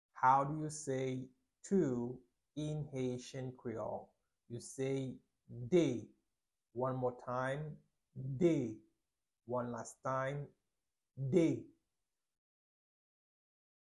Pronunciation:
5.How-to-say-two-in-haitian-creole-–-De-pronunciation.mp3